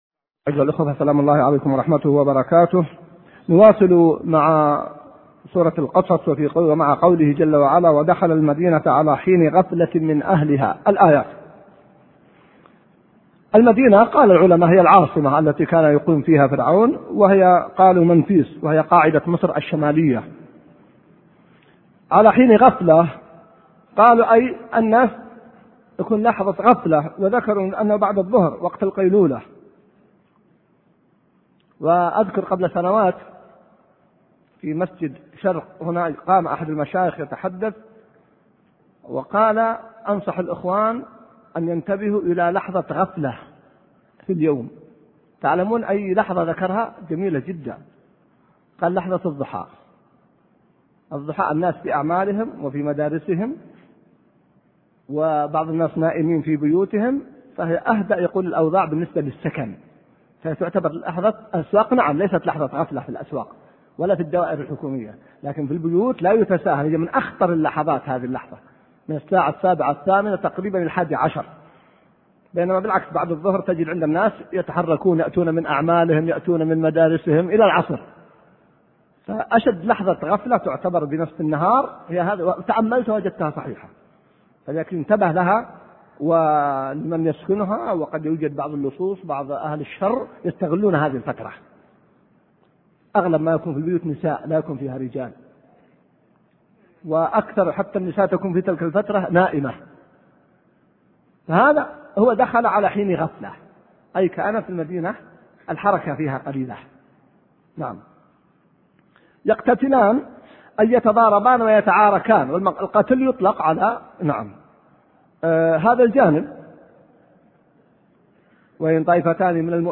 الدرس الثاني عشر من تفسير سورة القصص | موقع المسلم